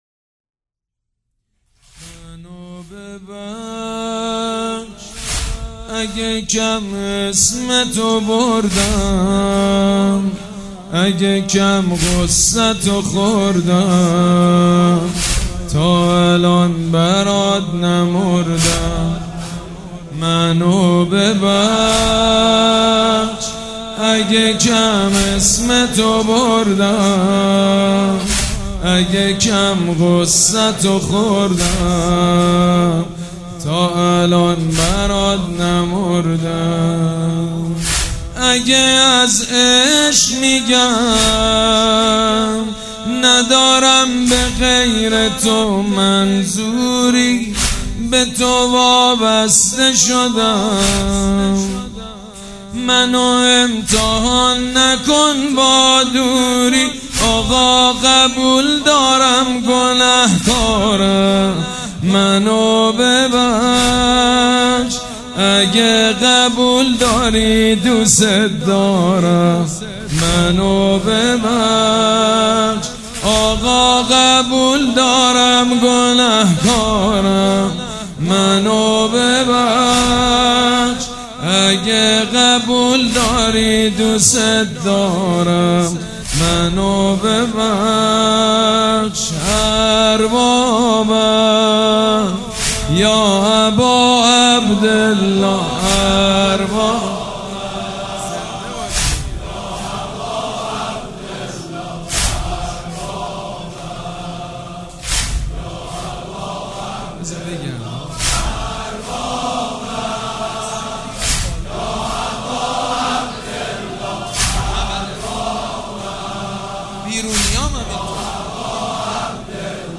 نوحه
نوحه سوزناک و فوق العاده